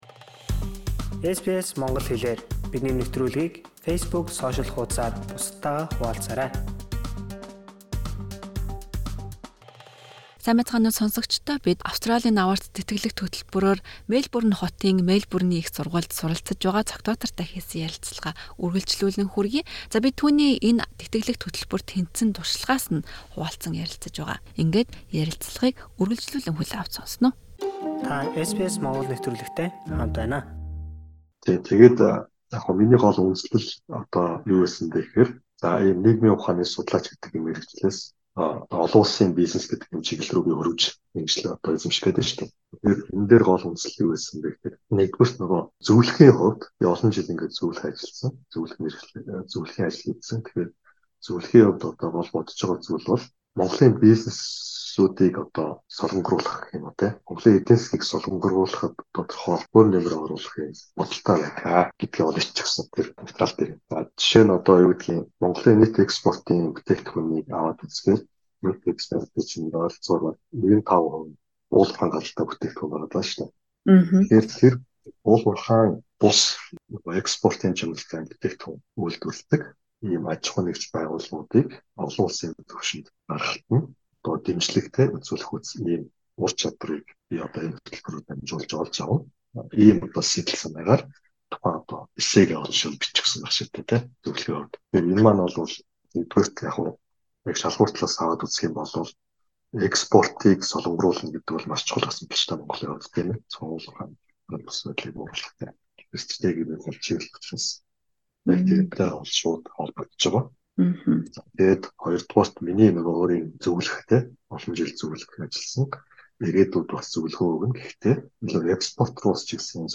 хийсэн ярилцлагын хоёр дах хэсгийг хүргэж байна.